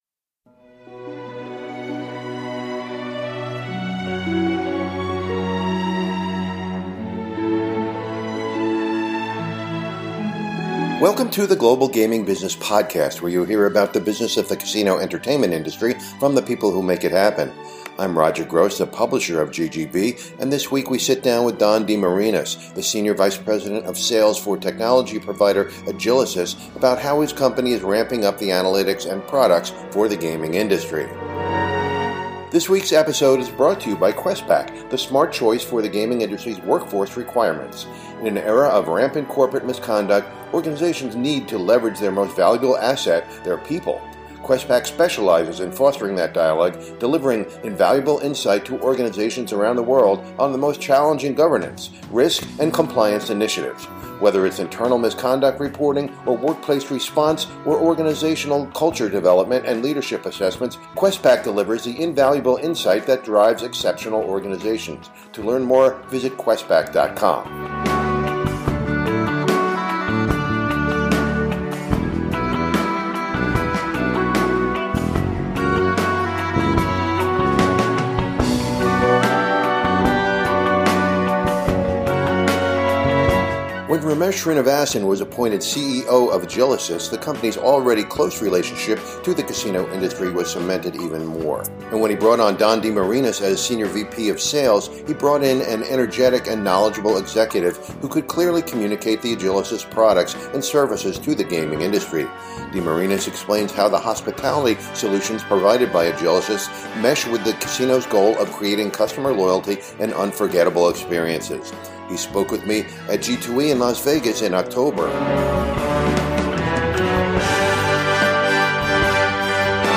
in Las Vegas at G2E in October